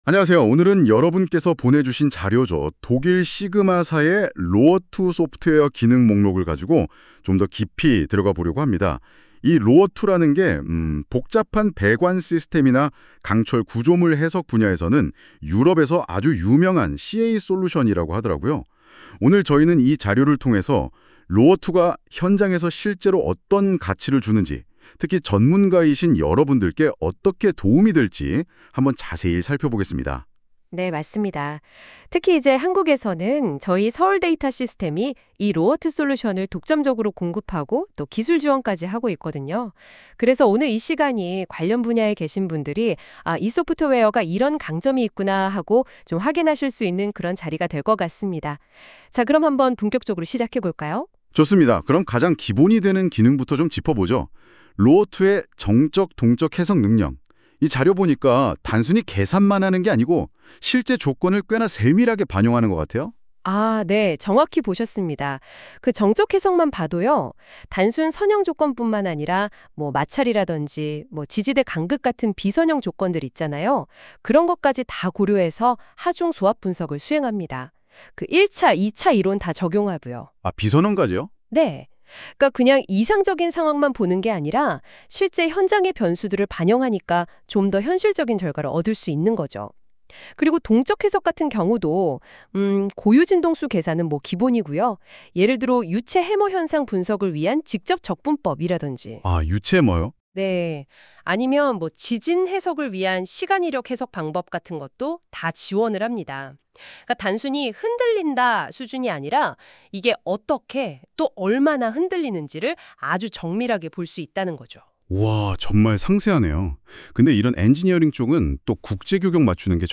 SIGMA의 소프트웨어를 AI 기반의 팟캐스트 형식으로 들어보세요.
각 제품의 핵심 개념과 차별점까지, 인공지능의 목소리로 안내해드립니다.